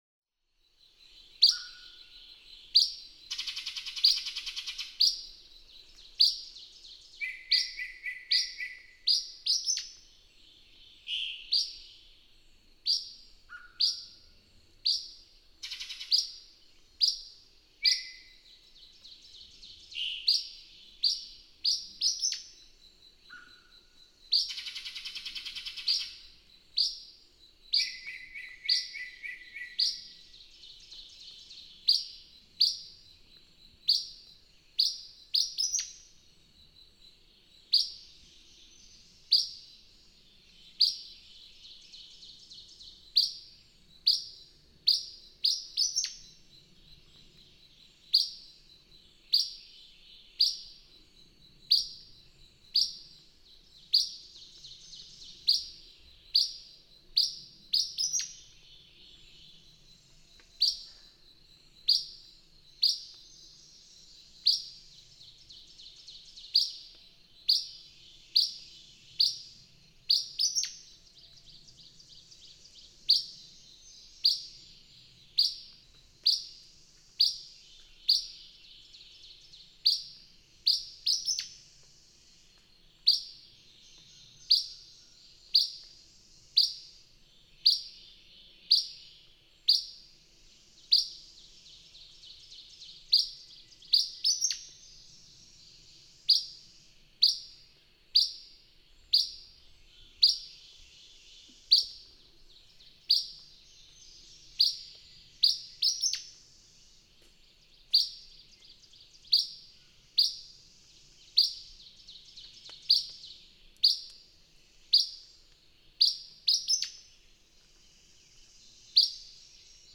Acadian flycatcher. Enjoy the early yellow-breasted chat in the background.
Ellington, Missouri.
631_Acadian_Flycatcher.mp3